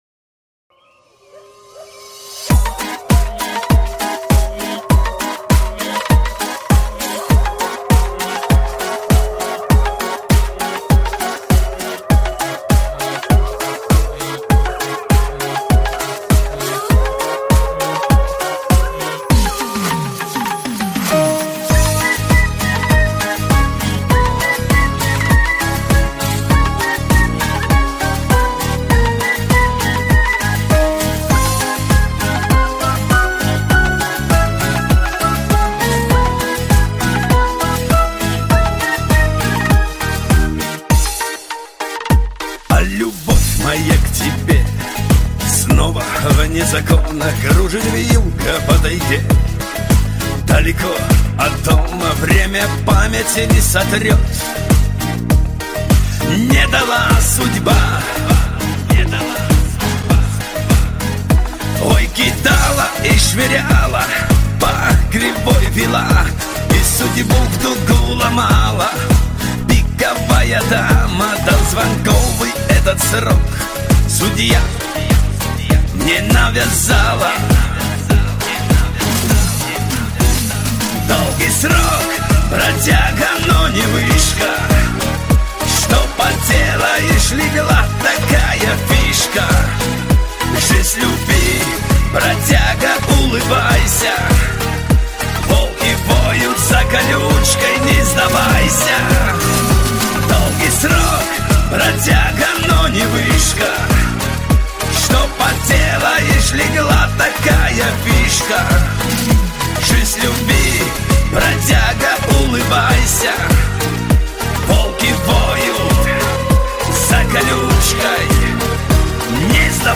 Блатной_Шансон_-_Бродяга